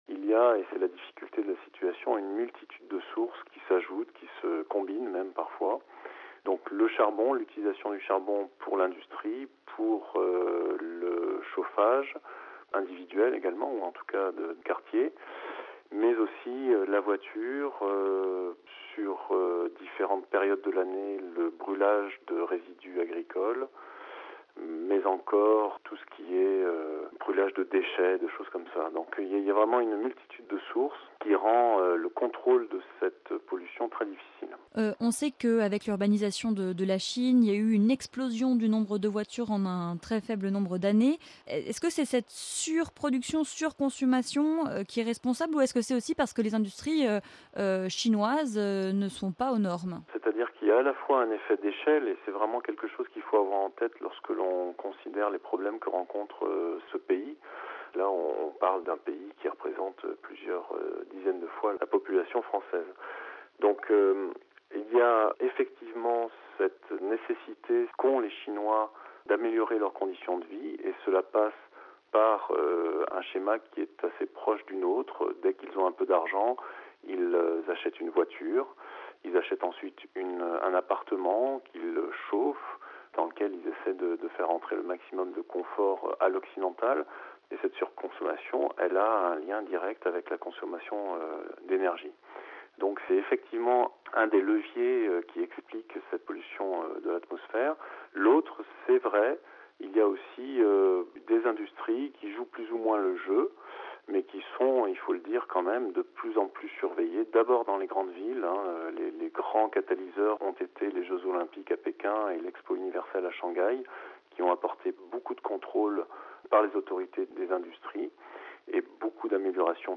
(RV) Entretien - La Chine suffoque : une épaisse couche de pollution atmosphérique recouvre 15 % du territoire.